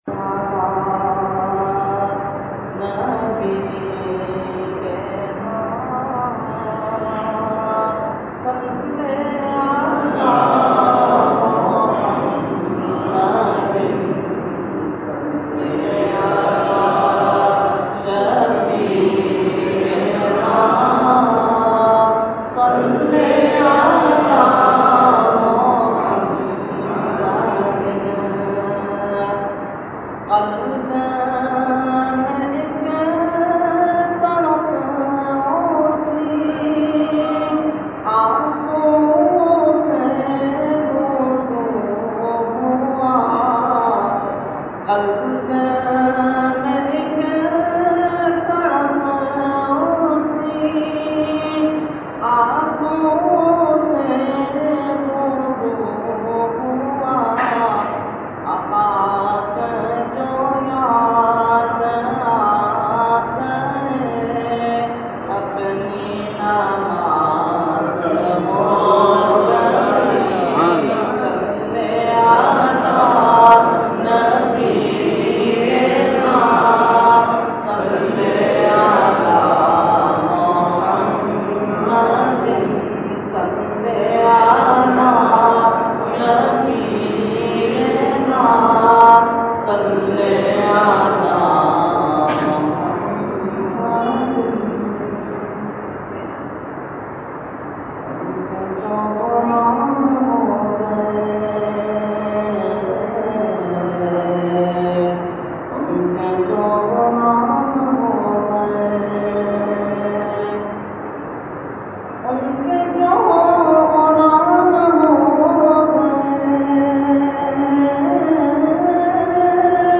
Category : Naat | Language : UrduEvent : Dars Quran Ghousia Masjid 24 June 2012